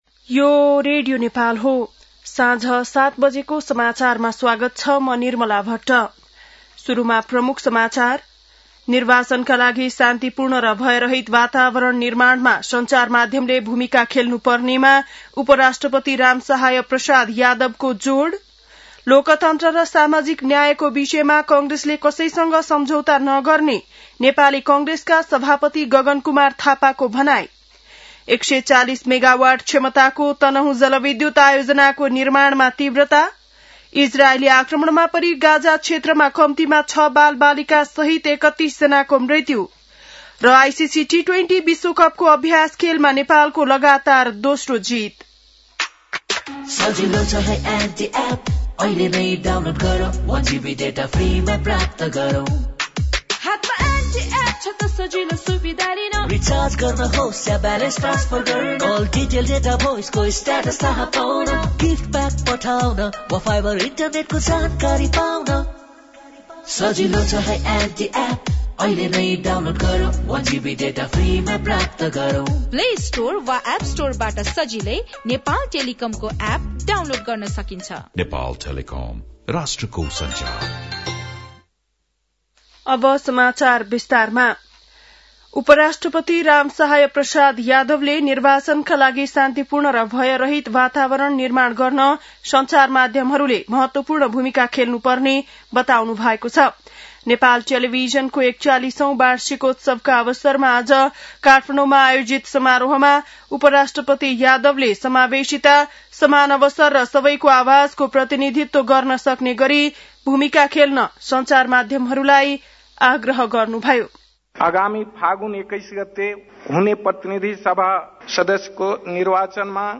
बेलुकी ७ बजेको नेपाली समाचार : १७ माघ , २०८२
7-pm-nepali-news-1-3.mp3